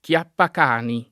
chiappacani [ k L appak # ni ] s. m.